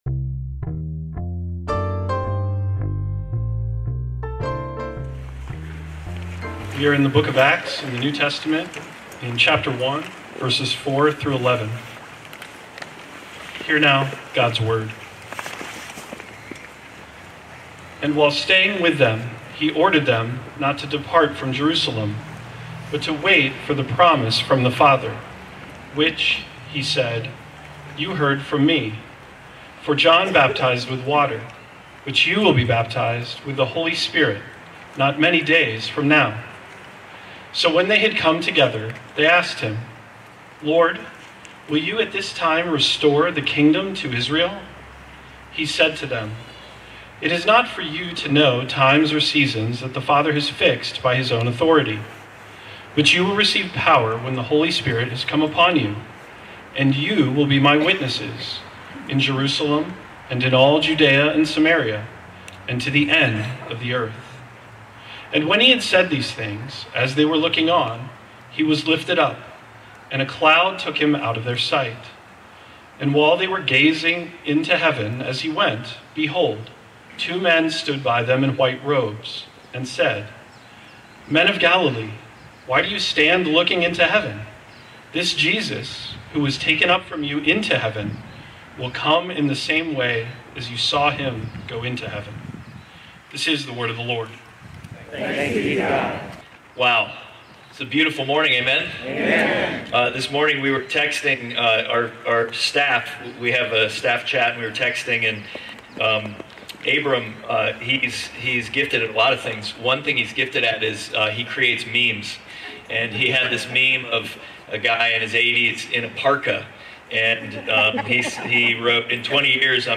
Single Series Sermon Passage: Acts 1:4-11 Service Type: Sunday Worship « Jesus’ Way of Leading The Third Person